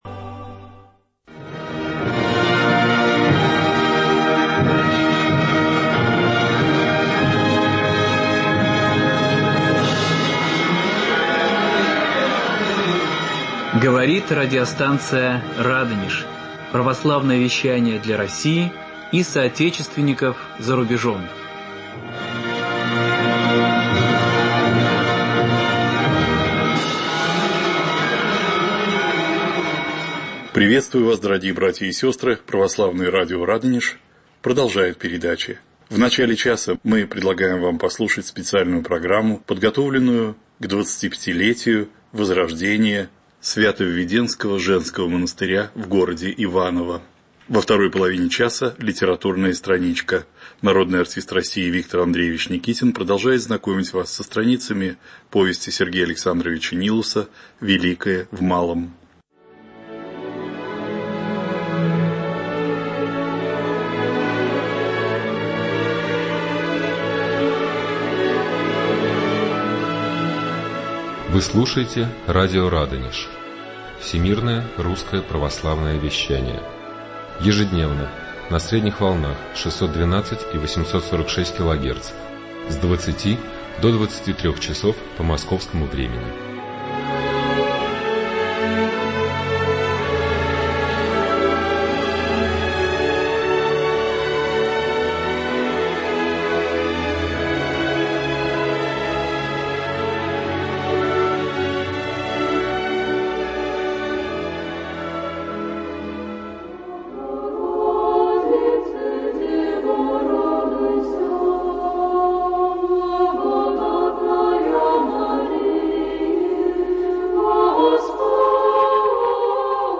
Слушать Скачать MP3-архив часа Специальная передача, посвященная возрождению Свято-Введенского женского монастыря в Иваново. Звучит хор сестер обители